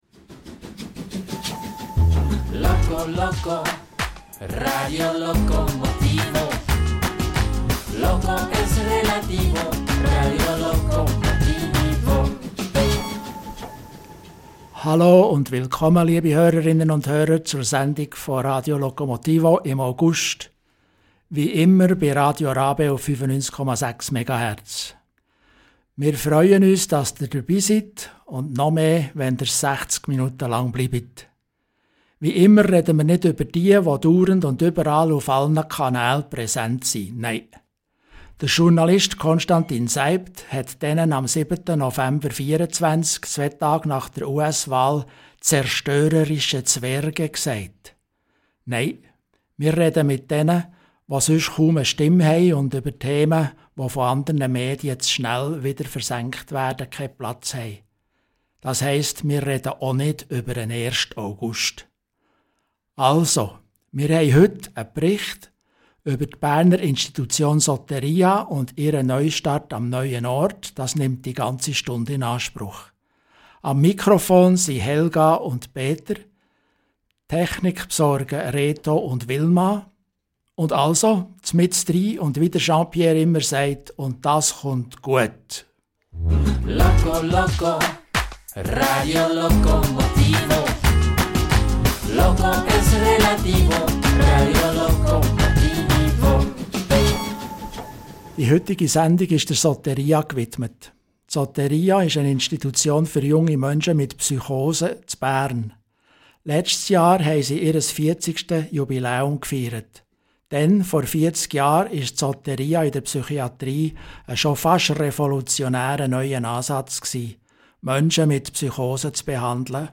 Zur Neueröffnung haben wir mit verschiedensten Menschen gesprochen, was für sie die Soteria ausmacht, und was sie von herkömmlichen psychiatrischen Institutionen unterscheidet.